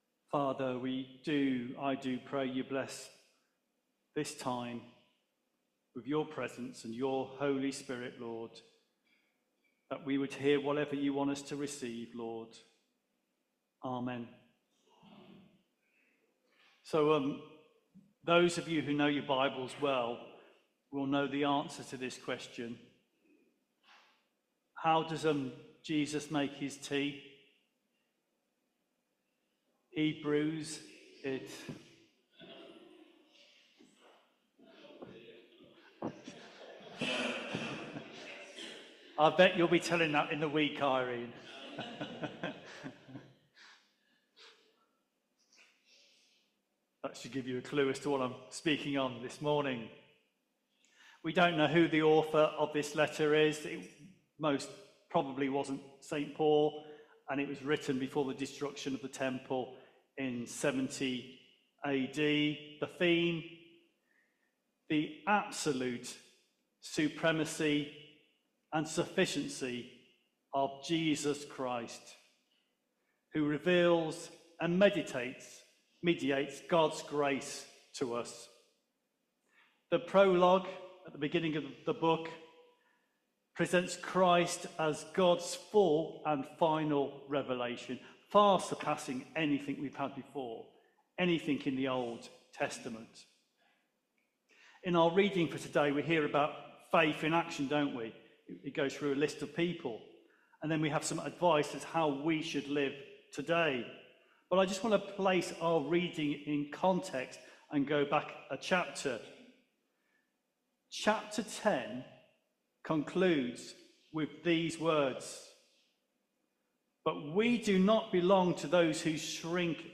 Media for Holy Communion on Sun 17th Aug 2025 09:00 Speaker